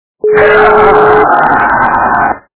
» Звуки » Из фильмов и телепередач » Смех - Папанова
При прослушивании Смех - Папанова качество понижено и присутствуют гудки.
Звук Смех - Папанова